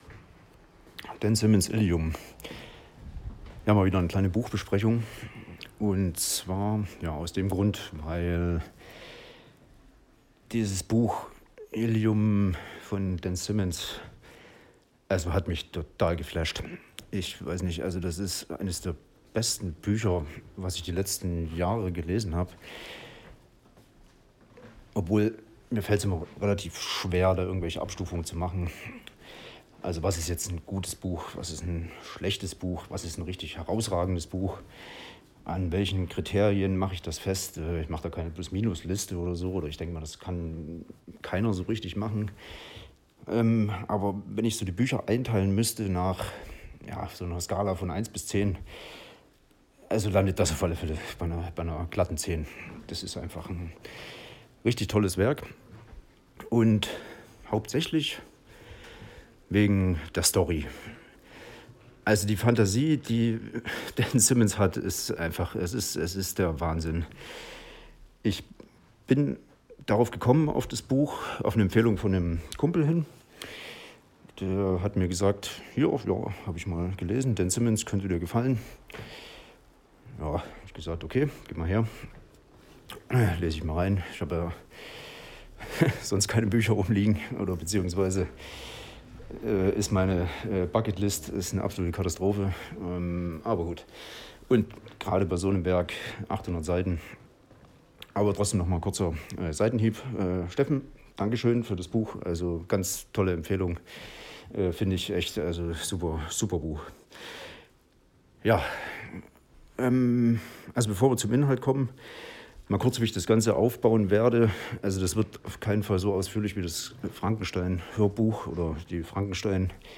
Mal wieder eine Buchbesprechung. Großartiges Buch, Mischung aus SciFi, Fantasy, mit Seitenausflügen in Historiendrama mit ner kleinen Prise Horror.